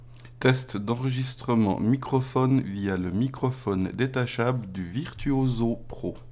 À savoir que ceux-ci ont été enregistré sans ajout d’effets audio.
Microphone détachable du Virtuoso Pro (avec et sans bonnette)
Les différences sont minimes et on notera juste un peu moins de graves et de profondeur de la voix sur le micro du Virtuoso Pro.